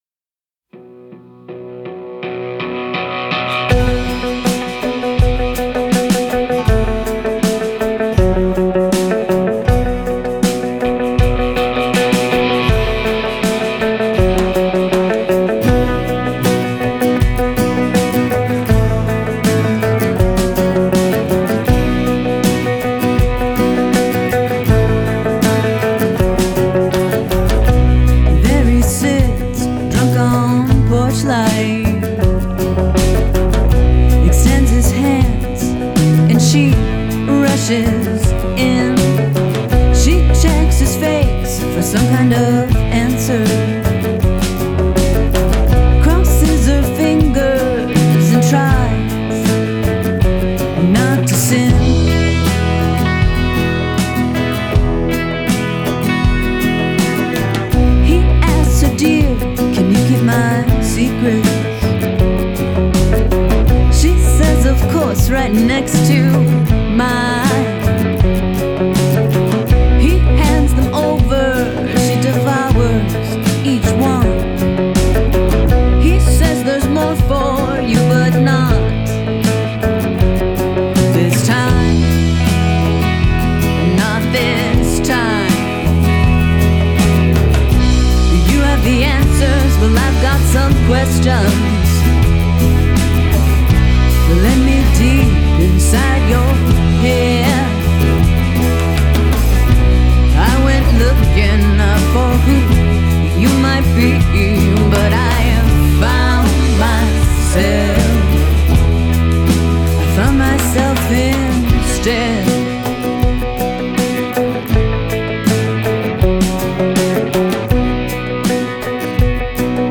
Genre: Pop, Rock